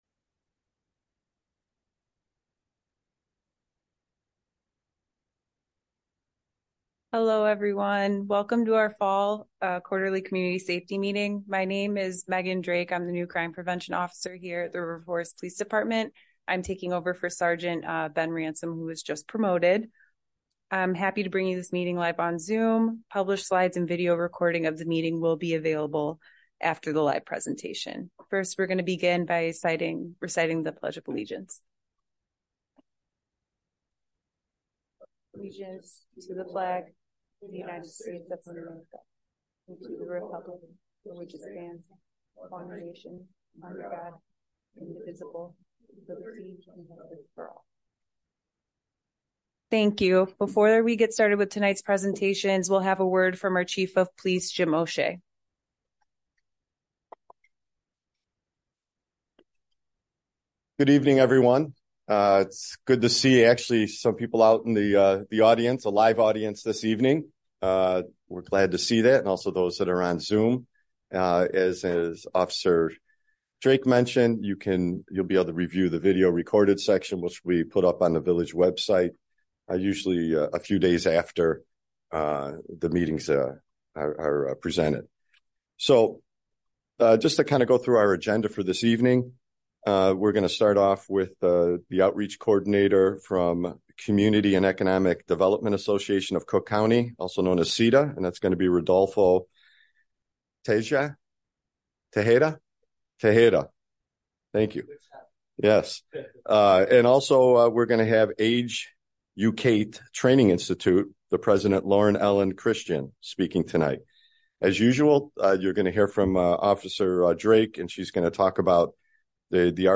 Quarterly Community Safety Meeting
Village Hall - 1st Floor - COMMUNITY ROOM - 400 Park Avenue - River Forest - IL
The purpose of the meeting is to update residents and encourage their involvement in the community while assisting the River Forest Police Department prevent, stop, and solve crime. We will conclude the meeting with an open forum for community member questions, comments, and concerns.